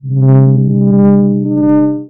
scan.wav